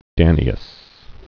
(dănē-əs)